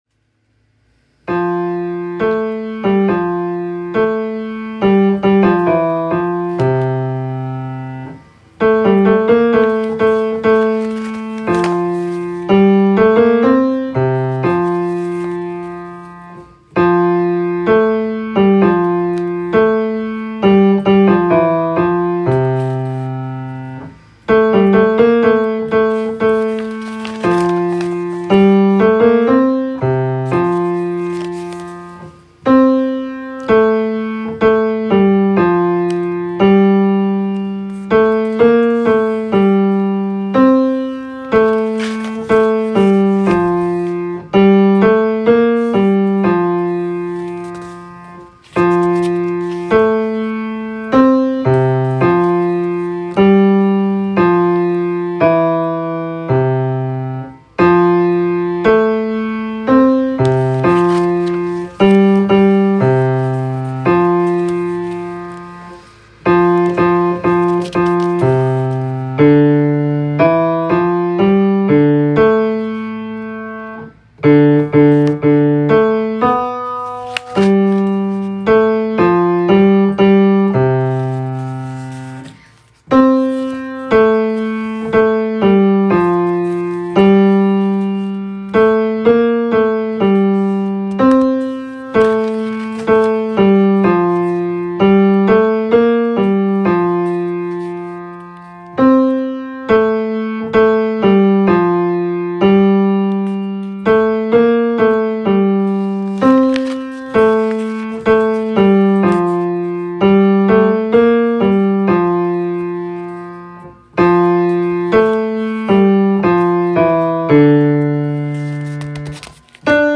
Lift Up Your Voices Bass.m4a